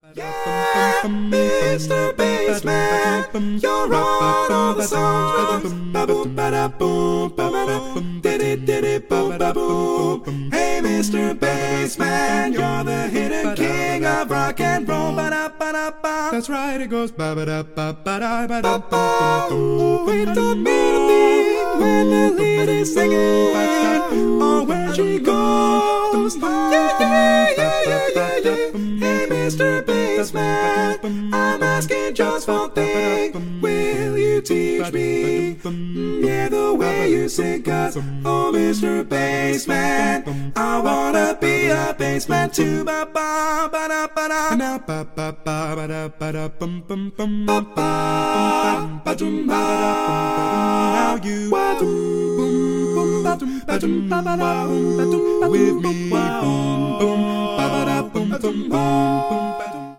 Full mix only
Category: Female